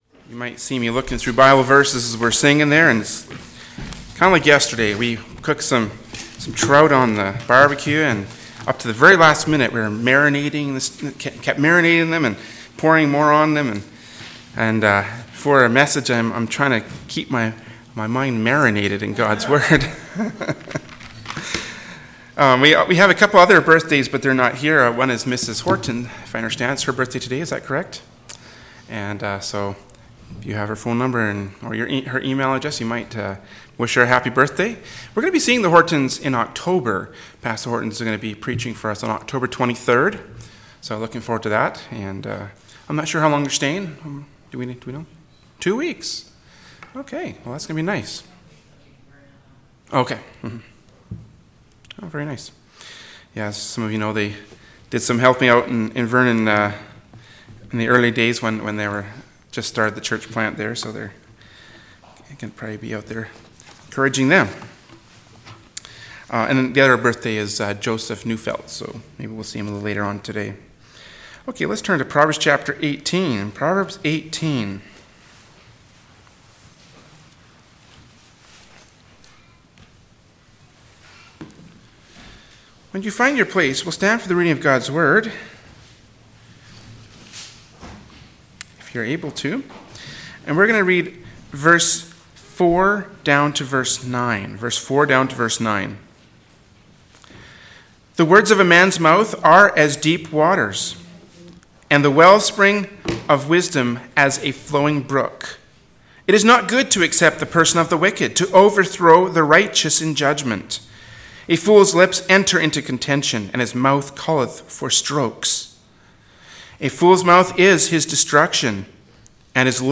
“Proverbs 18:4-9” from Sunday School Service by Berean Baptist Church.